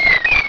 azurill.wav